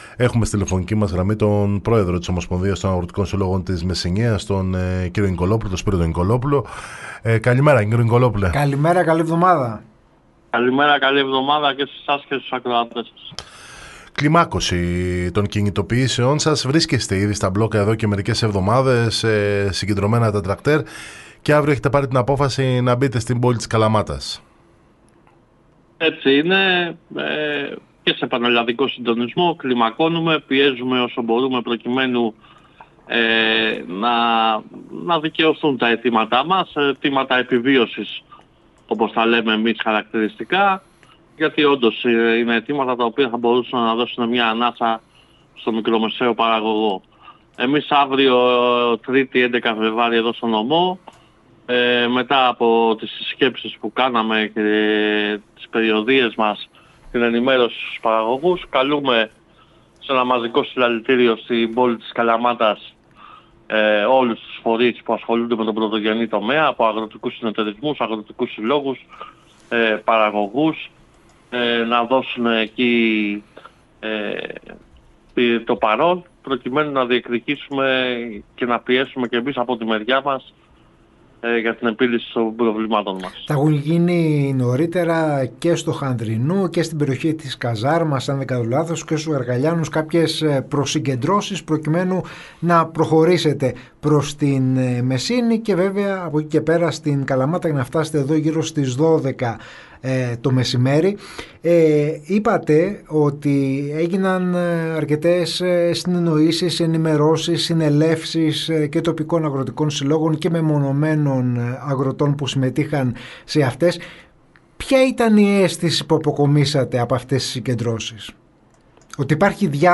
Ακούστε αναλυτικά όλα όσα είπε στο ραδιόφωνο της ΕΡΤ Καλαμάτας,